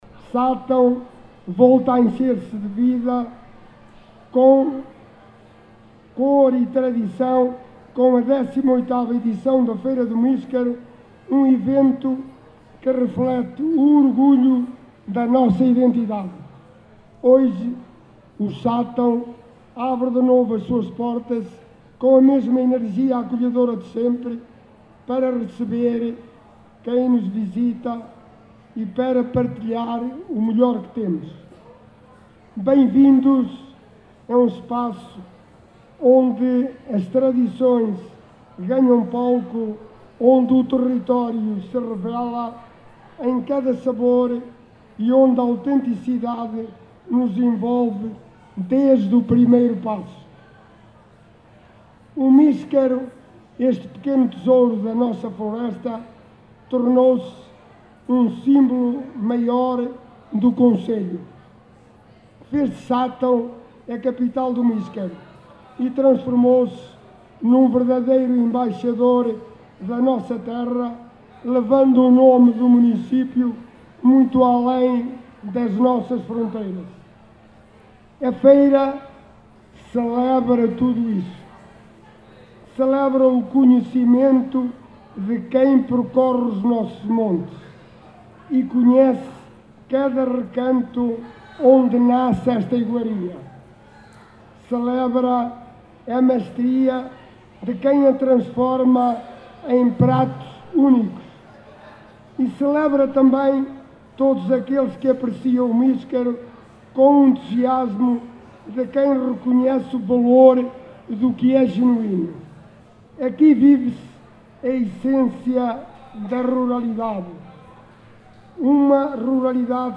Numa organização do Município de Sátão, este domingo, 30 de novembro, a XVIII Feira do Míscaro que decorreu no Largo de São Bernardo, voltou a reunir muitos visitantes, oriundos do concelho, da região e do país.
Alexandre Vaz, Presidente do Município, no seu discurso de abertura deste certame, referiu que a vila de Sátão, volta a refletir o orgulho da sua identidade, com a mesma energia acolhedora de sempre.